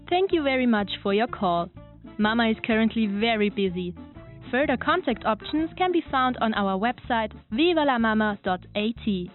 Voice Over
Telefonansage (Englisch)